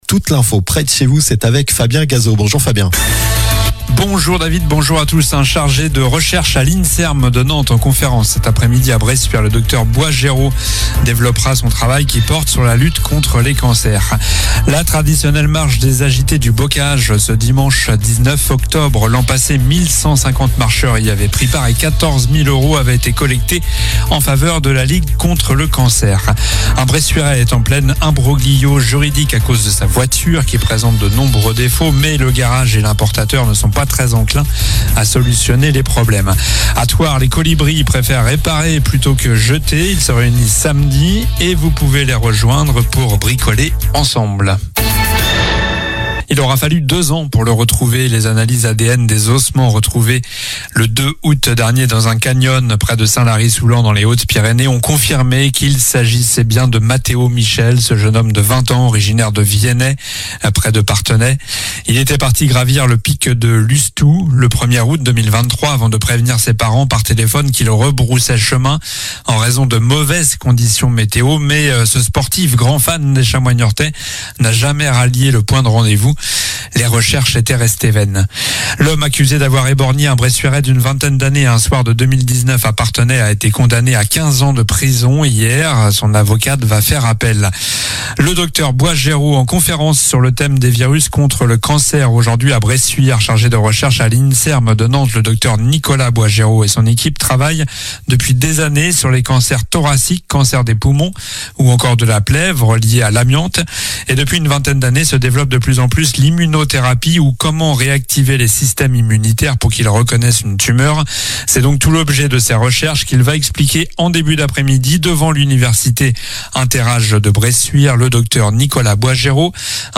Journal du jeudi 16 octobre (midi)